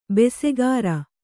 ♪ besegāra